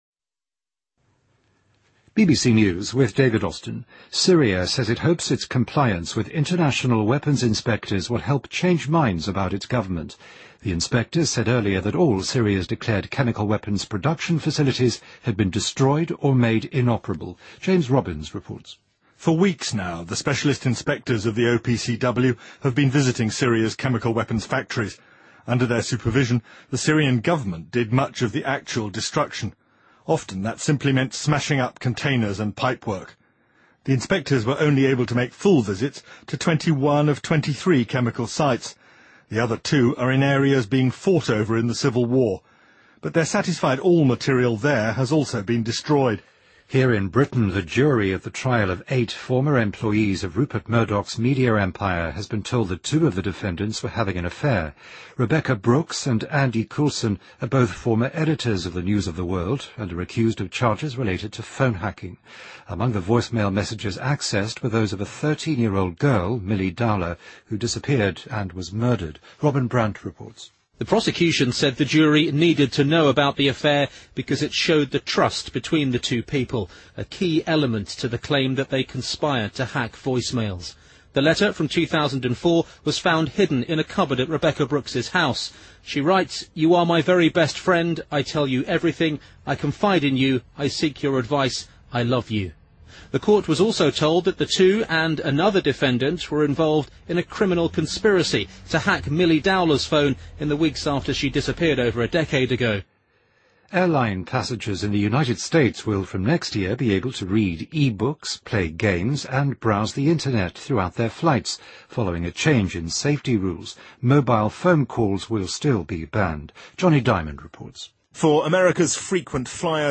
BBC news,美国空管局解除电子设备禁令